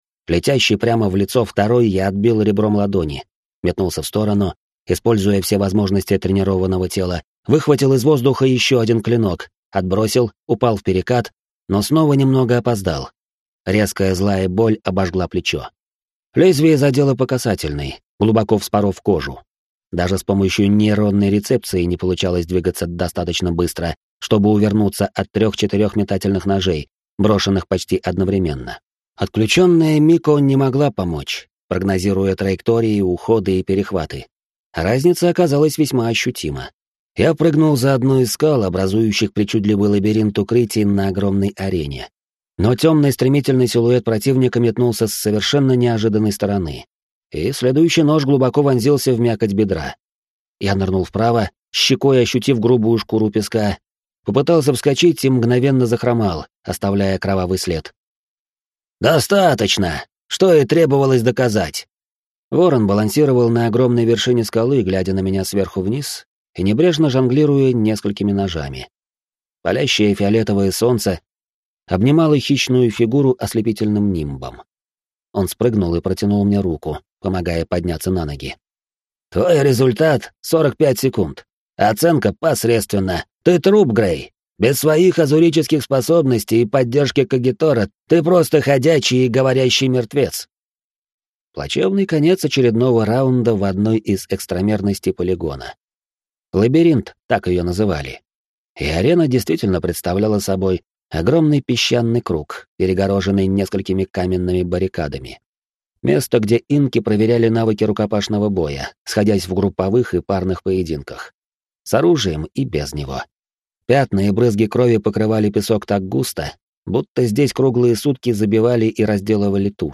Аудиокнига Стеллар. Эфемер | Библиотека аудиокниг